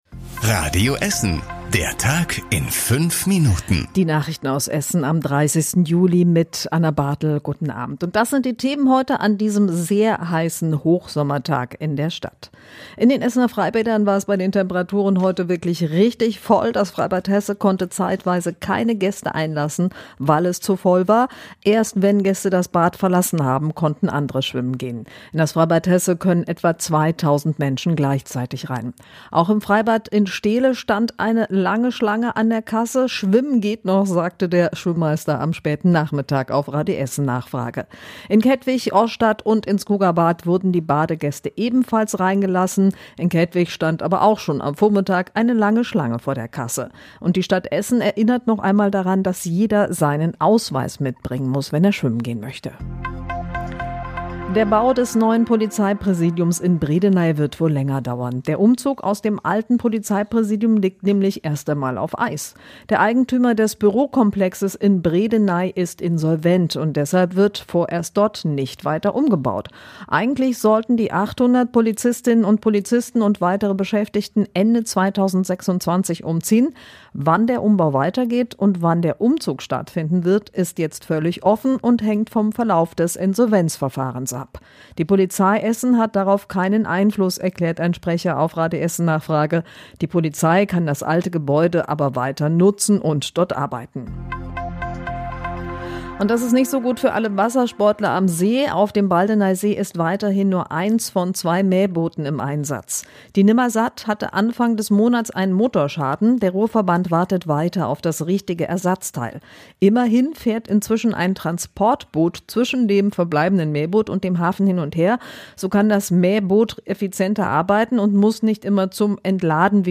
Die wichtigsten Nachrichten des Tages in der Zusammenfassung